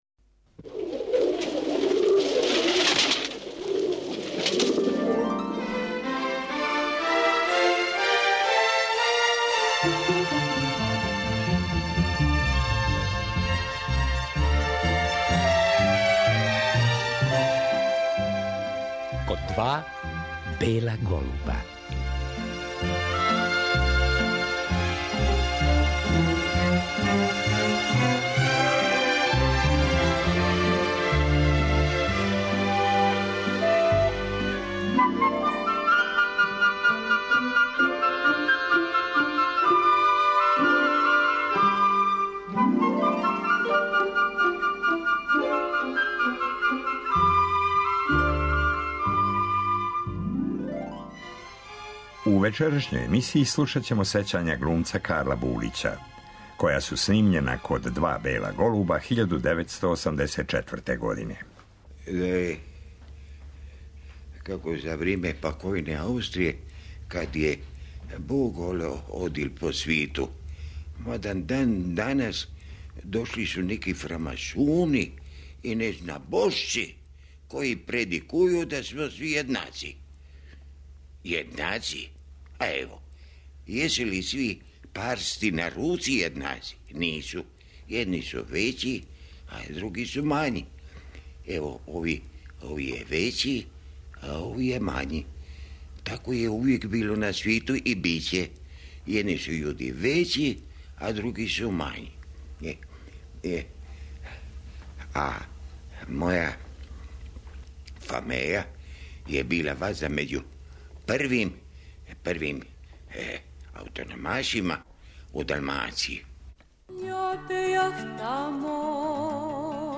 Карло Булић био је гост ове емисије 1984. године. Причао нам је о свом првом сусретом са Београдом 1926. године, о доласку у Југословенско драмско позориште и својим улогама.
Чућемо одломак из ТВ серије "Наше мало мисто" у којој је Булић ненадмашно одиграо улогу доктора Луиђија.